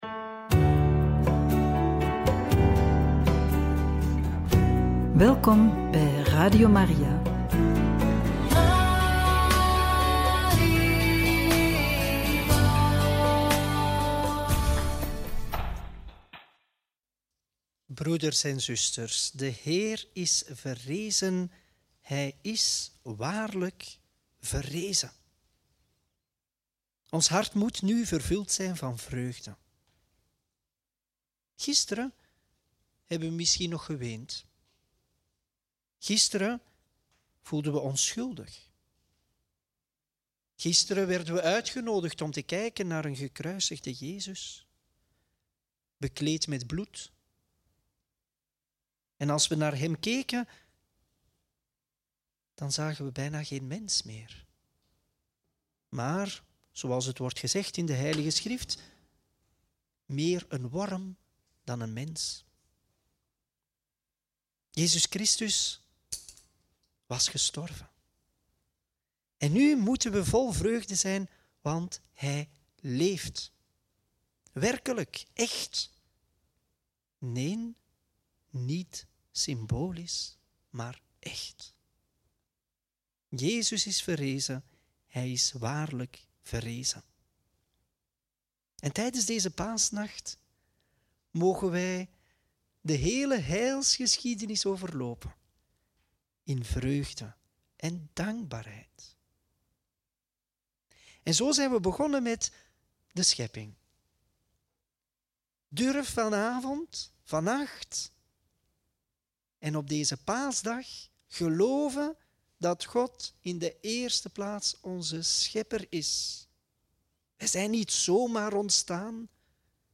Homilie tijdens de Paaswake – Lc 24,1-12
homilie-tijdens-de-paaswake-lc-241-12.mp3